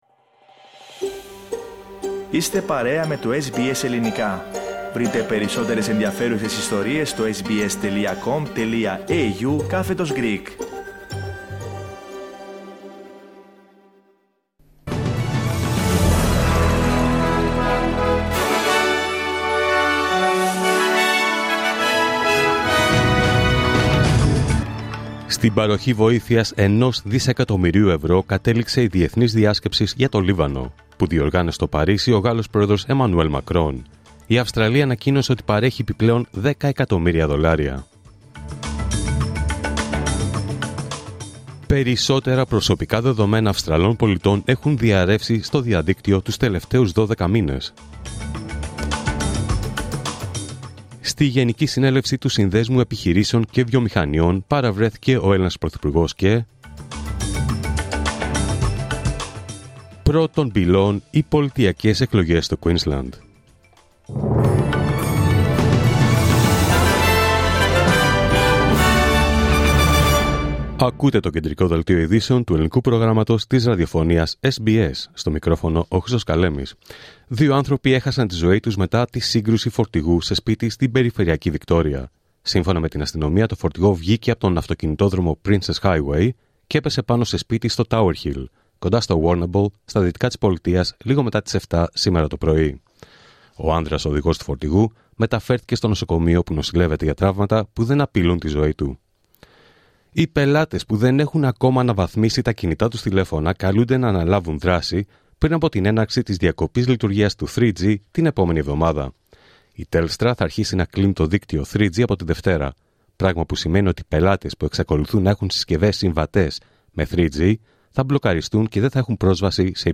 Δελτίο Ειδήσεων Παρασκευή 25 Οκτώβριου 2024